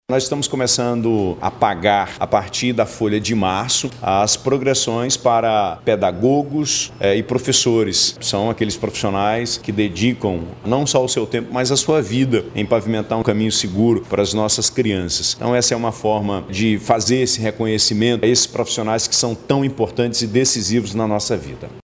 Em seu pronunciamento, o governador Wilson Lima destacou a importância dos professores e pedagogos, afirmando que a medida é uma forma de reconhecer o esforço desses profissionais que dedicam suas vidas à educação das crianças.